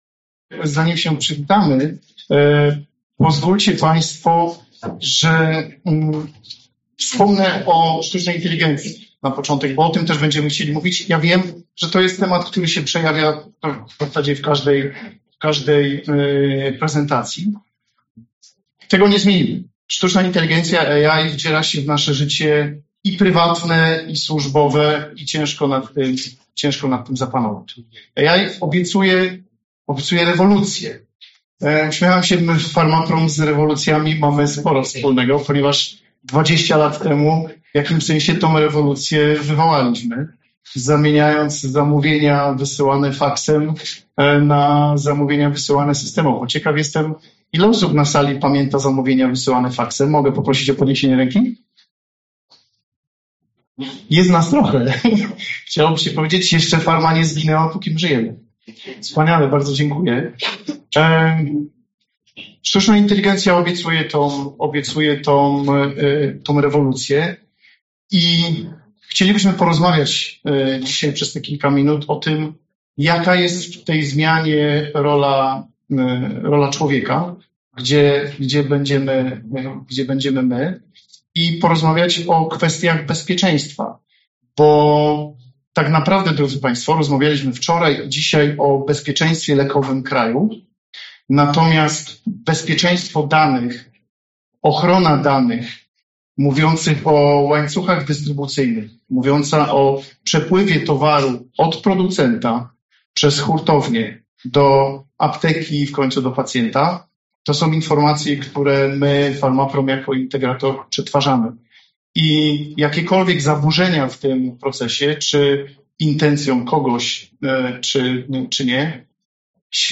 Posłuchaj wystąpienia z VII Forum Pharma Planet 360°.
farmaprom_konferencja.mp3